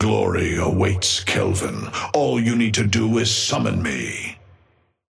Amber Hand voice line - Glory awaits, Kelvin.
Patron_male_ally_kelvin_start_04.mp3